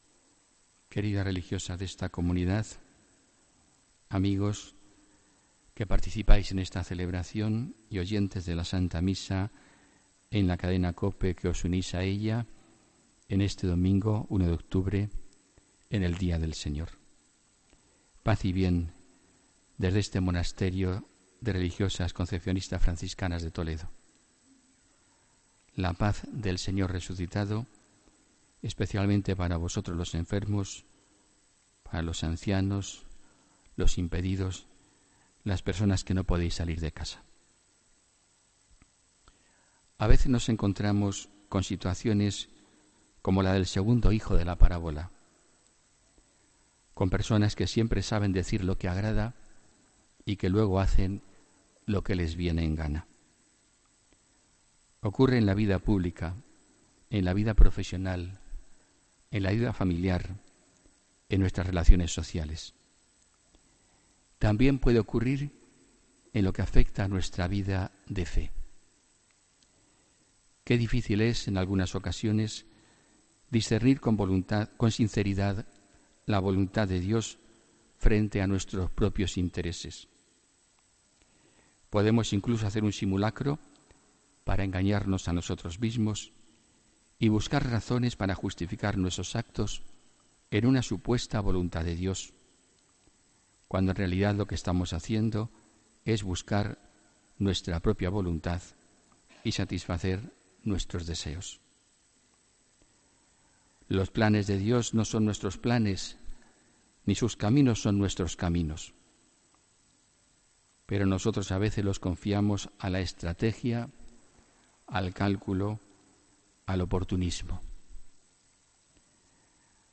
Homilía del domingo 1 de octubre de 2017